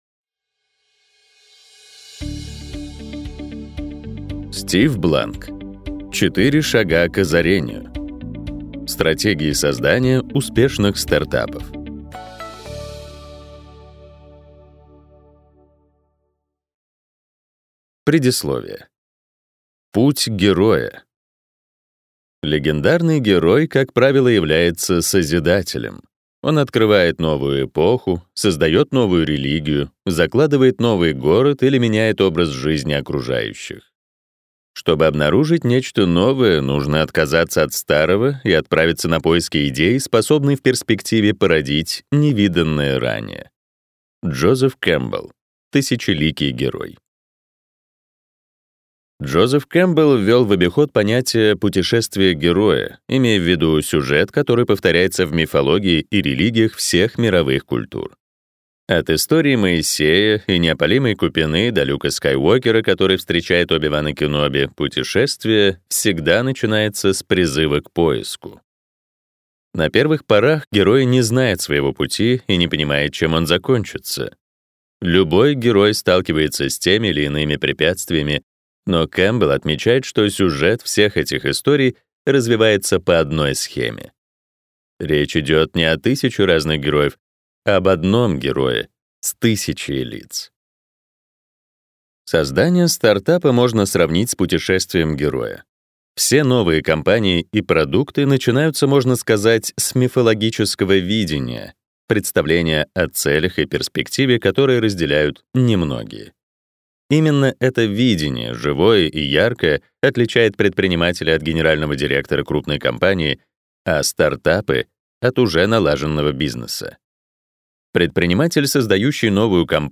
Аудиокнига Четыре шага к озарению. Стратегии создания успешных стартапов | Библиотека аудиокниг